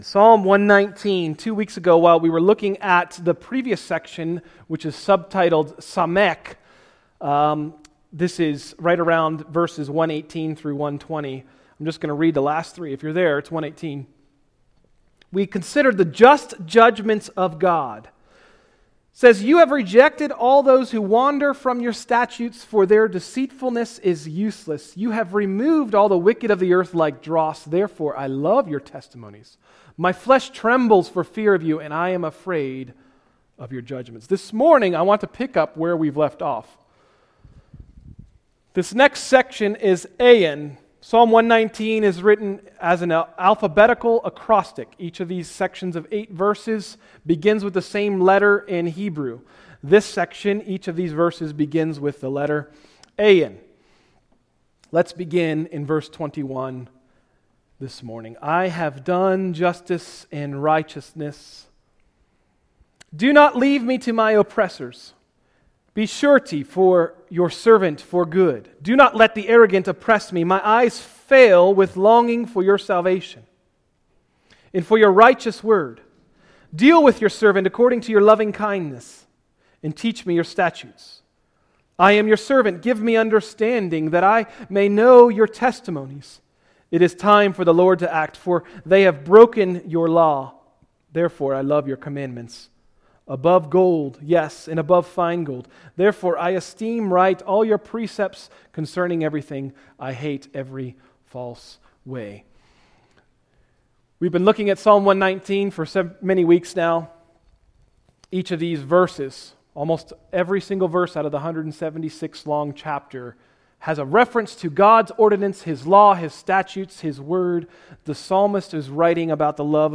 Message: “Ayin: Psalm 119” – Tried Stone Christian Center